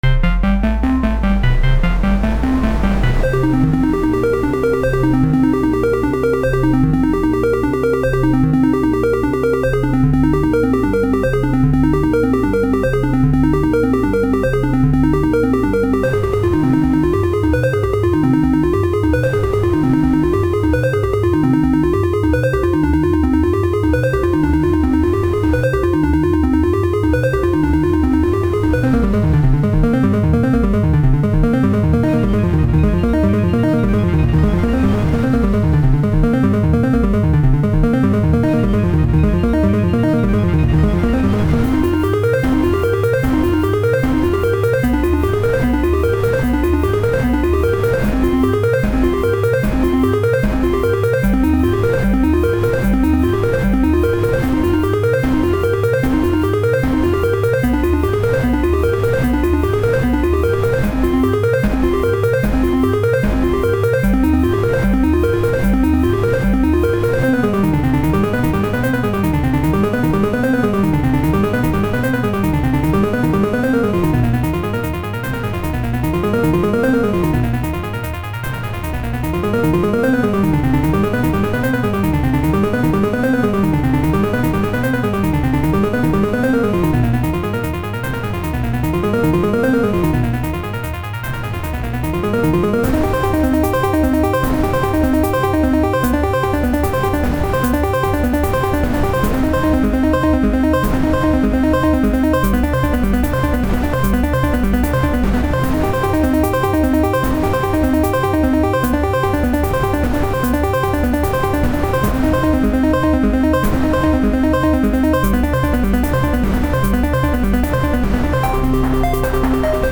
guitar.mp3